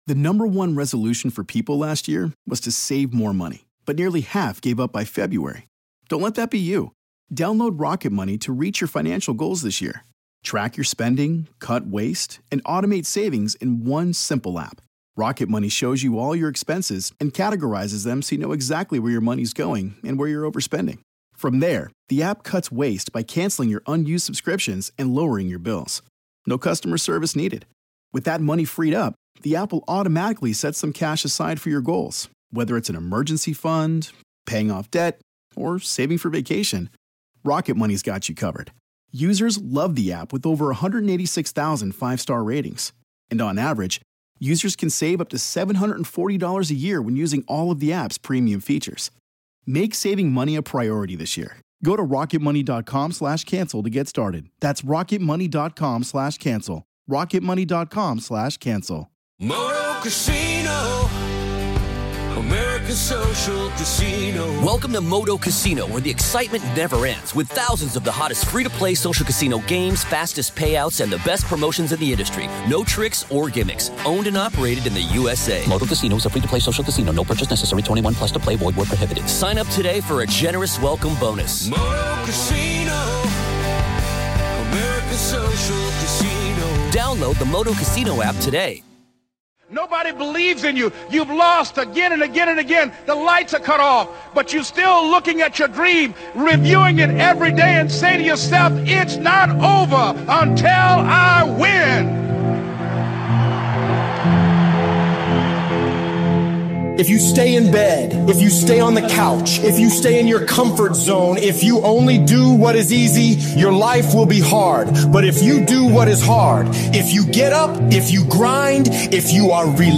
If you’ve ever doubted yourself or been told you can’t make it, let this episode remind you that it’s not over until you win. With intense speeches and powerful visuals, this compilation is a journey to unlocking your inner power and stepping fully into the person you were meant to be.